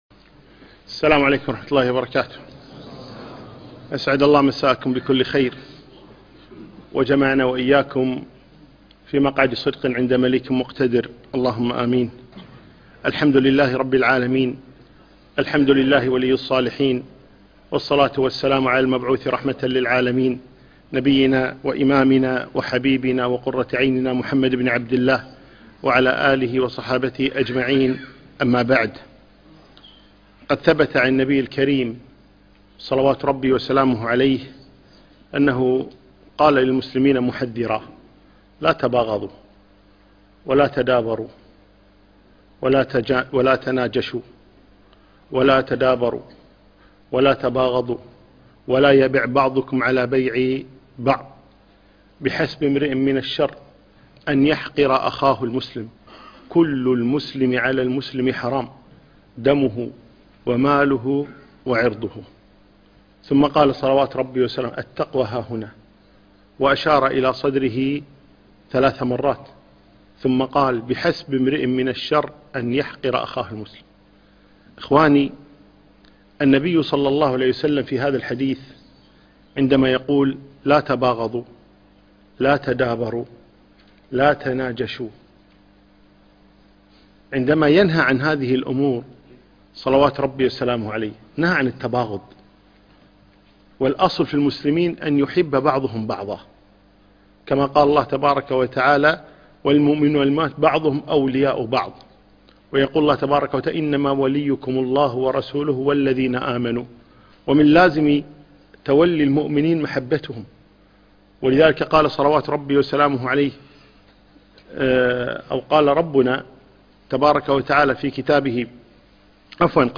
أمسك عليك لسانك - شرح أحاديث نبوية